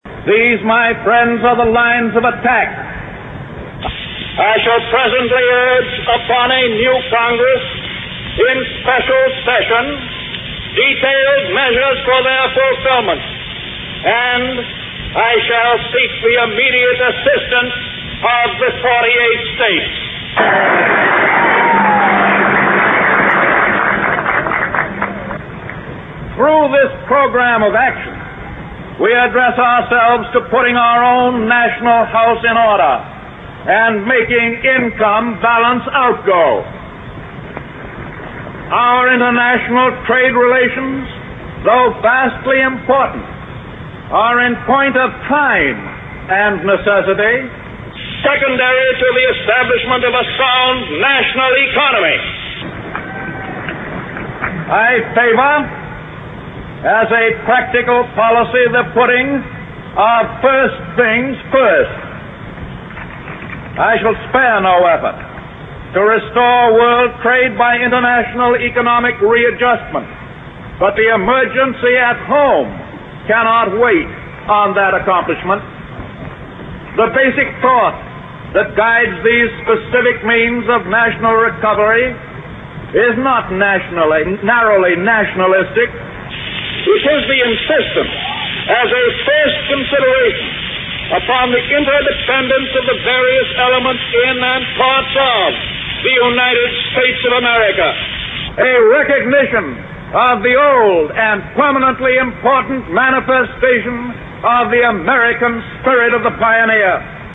名人励志英语演讲 第22期:我们唯一害怕的是害怕本身(7) 听力文件下载—在线英语听力室